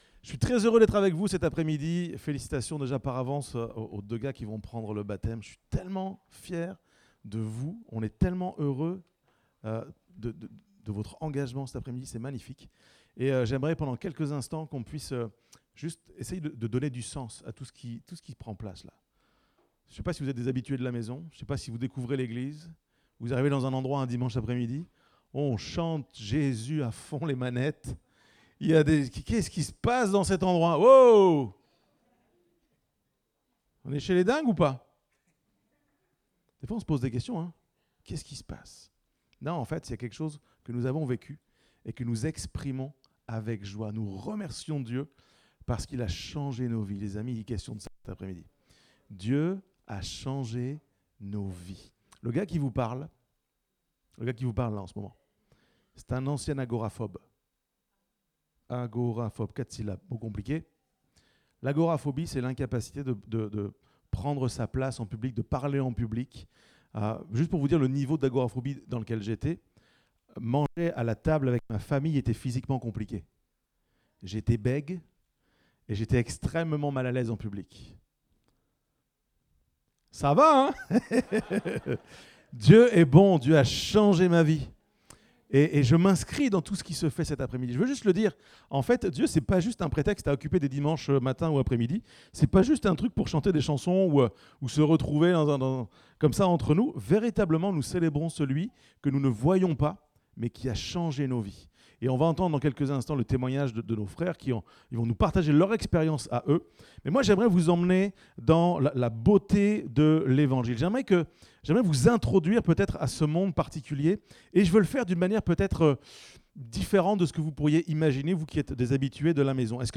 Date : 22 septembre 2024 (Culte Dominical)